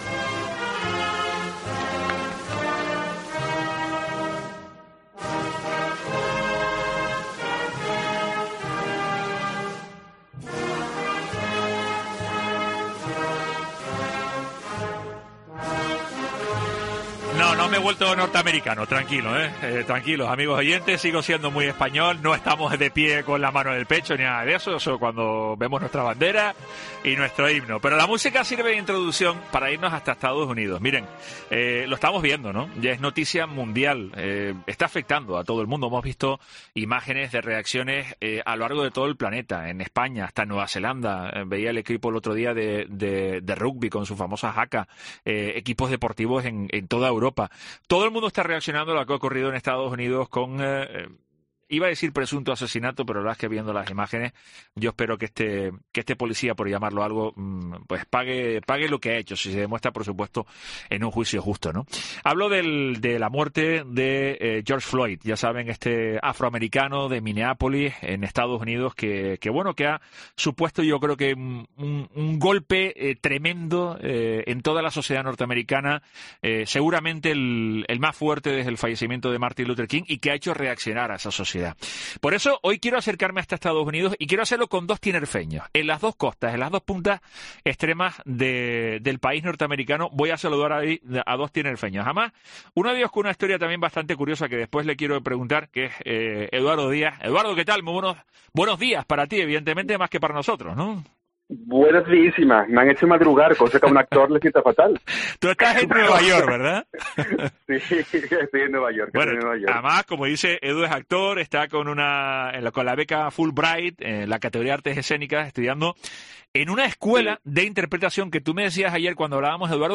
Son algunas de las conclusiones, que hoy, en nuestro programa La Mañana de Cope Tenerife, hemos sacado con dos tinerfeños, que residen en los Estados Unidos de Ámerica, uno en la costa oeste, en la meca de las empresas tecnológicas, en Silicon Valley y otro en pleno downtown de Nueva York, en mismísimo Manhattan.